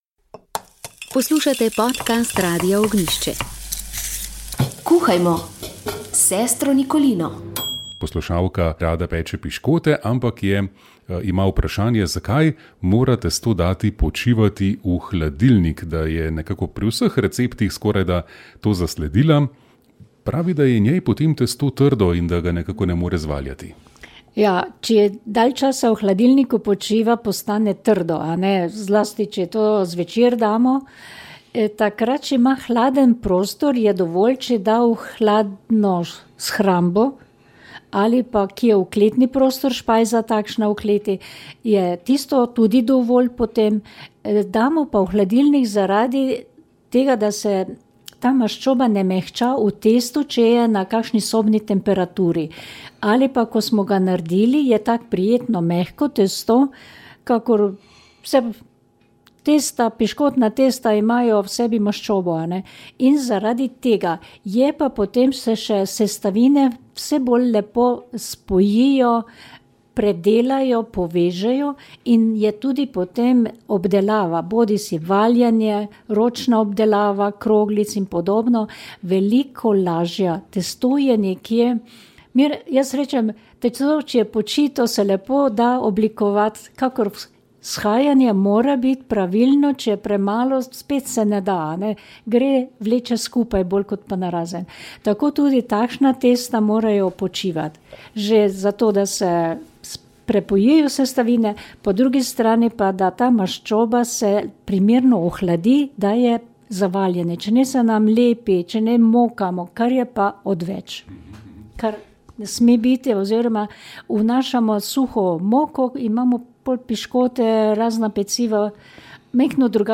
Z mikrofonom med udeleženci in obiskovalci Ritma srca